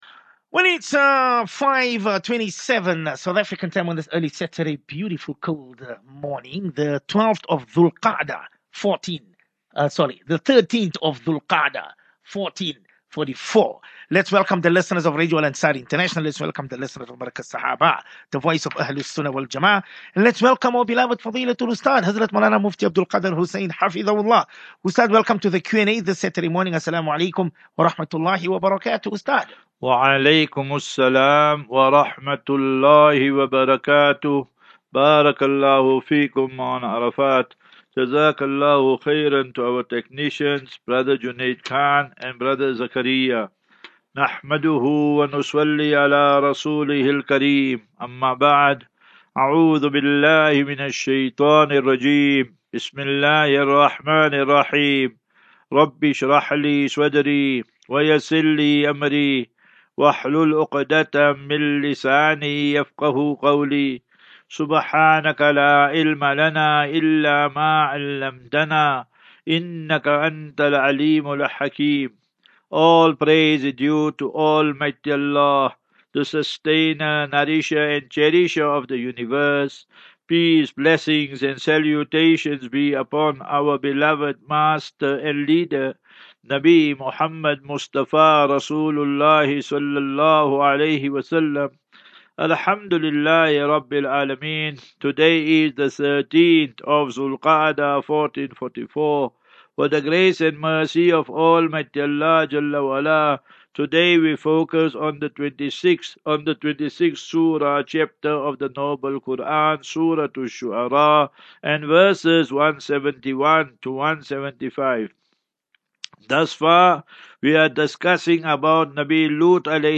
Daily Naseeha.
As Safinatu Ilal Jannah Naseeha and Q and A 3 Jun 03 June 23 Assafinatu